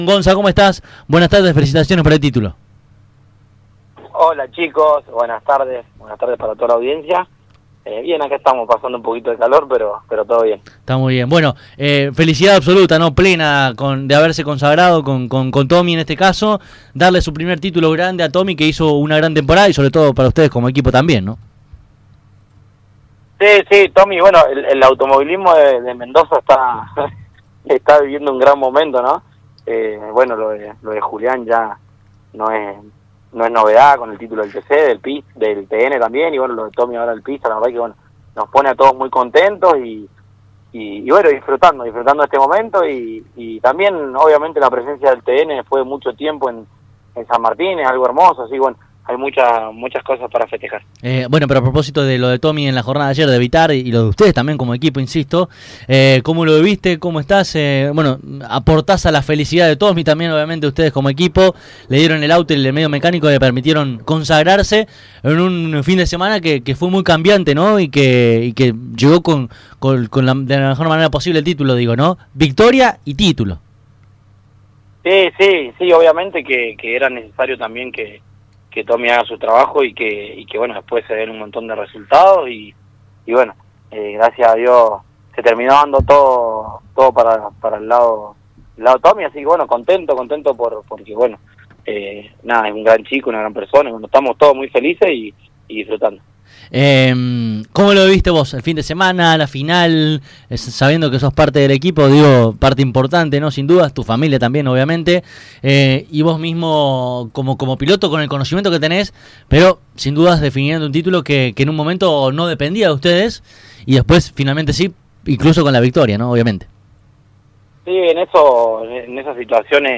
Córdoba Competición noviembre 24, 2025 AUDIOS, Clase 2, NACIONALES, OTRAS, TN